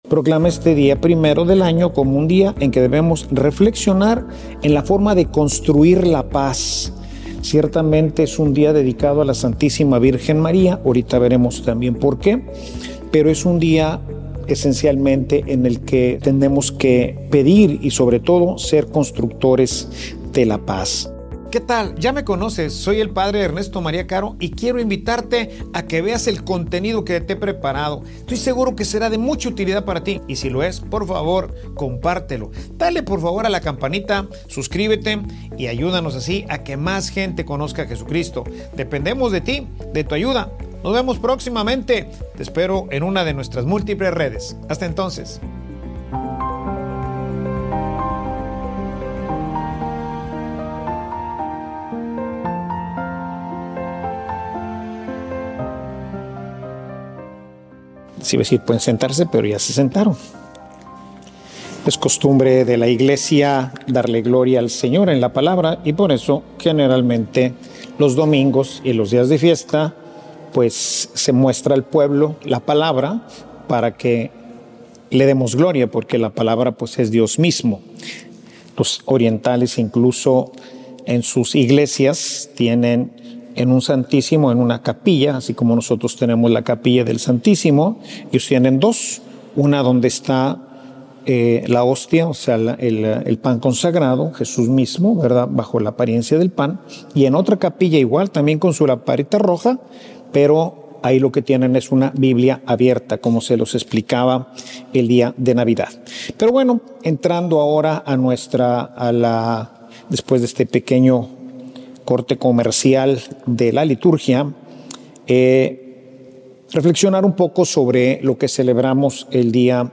Homilia_Maria_instructivo_para_alcanzar_la_paz.mp3